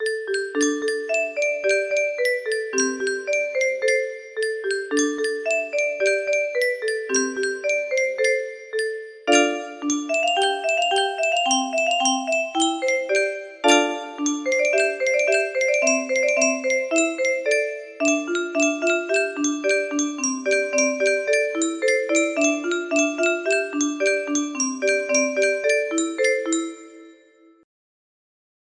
pookie music box melody